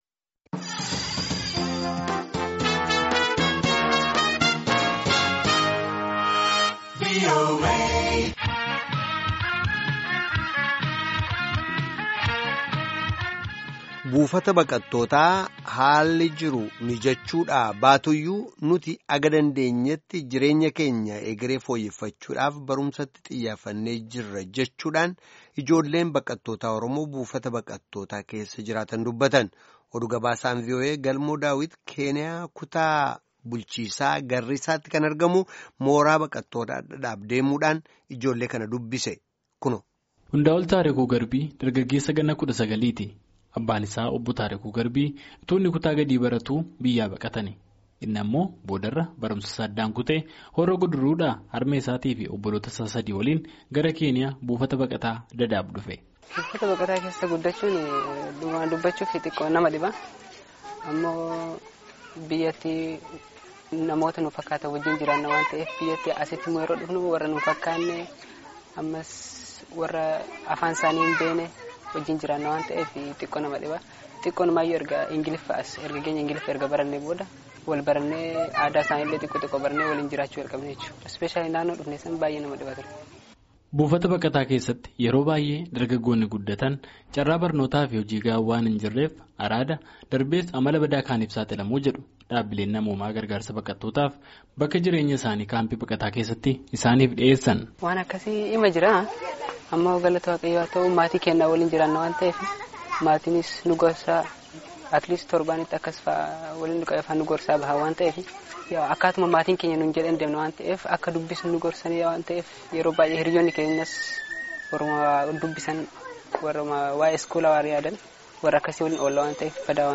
DADAAB, KENIYAA —
Keeniyaa Kutaa bulchiinsa Garrisaatti ka argamu kaampii baqataa Dadaab dhaqee ijoollee kana faana haasayee jira